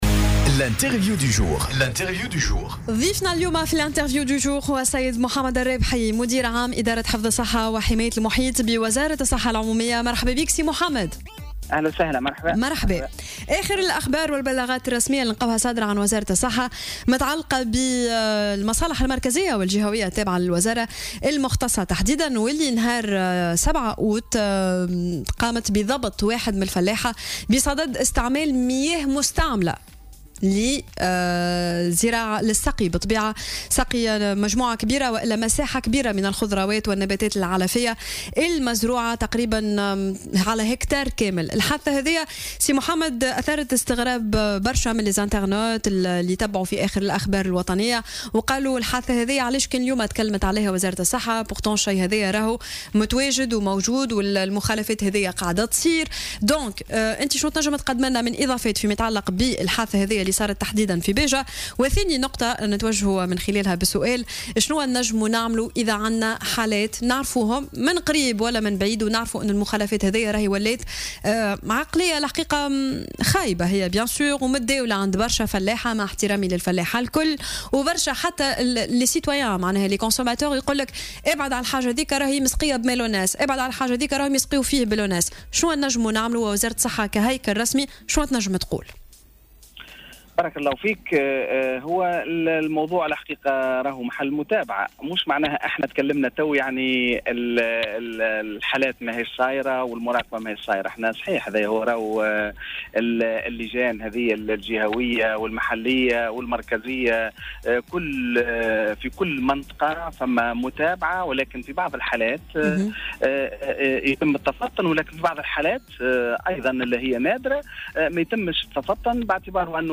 وأكد من خلال برنامج "صباح الورد" على "الجوهرة اف أم" في تعليقه على حادثة استعمال فلاح لمياه لصرف الصحي بباجة على ضرورة تظافر الجهود للتصدي لمثل هذه الممارسات. وأوضح أن ريّ الزراعات باستخدام المياه المستعملة الخام ممنوع منعا باتا في أي مجال لما يفرزه من أوبئة وانتقال للجراثيم.